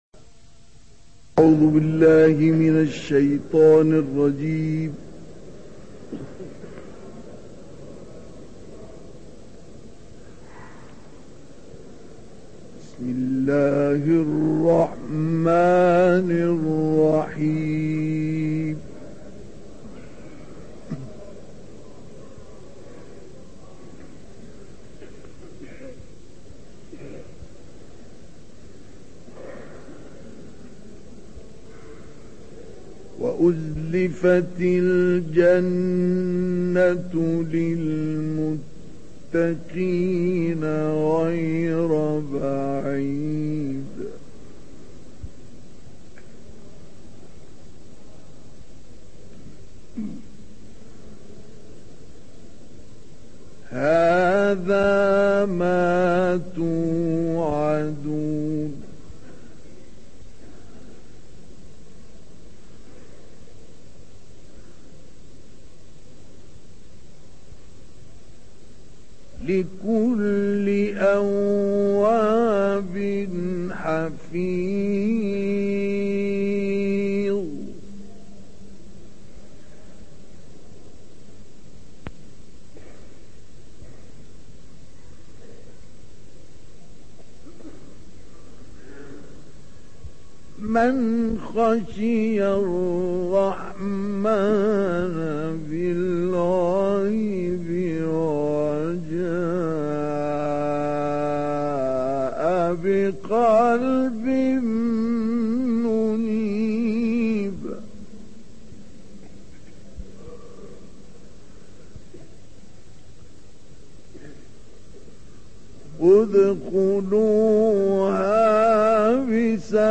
Recitation by Sheikh Mustafa Ismail
Sheikh Mustafa Ismail was a Qari who introduced an innovative style of recitation in which he somehow would illustrate the concepts of the verses through his beautiful voice.